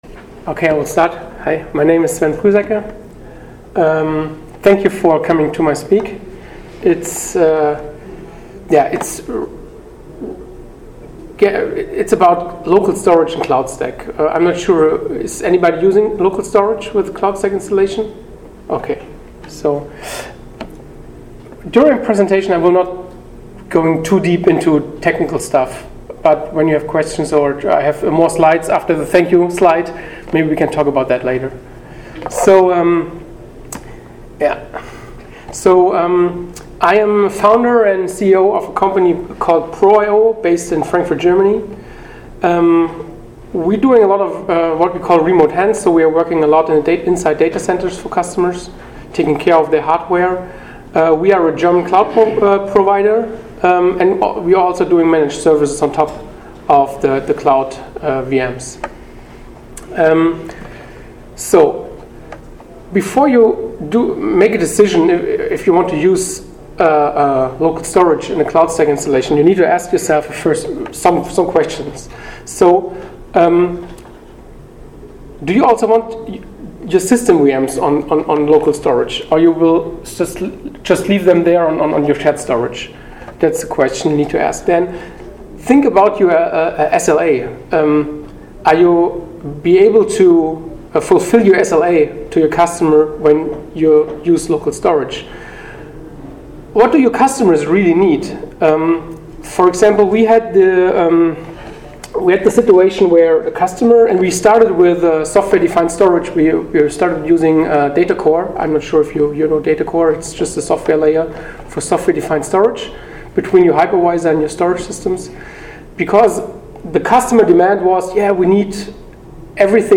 ApacheCon Miami 2017 - Pros and Cons of Using Cloudstack with Local Storage
proIO GmbH Cloudstack Collaboration Conference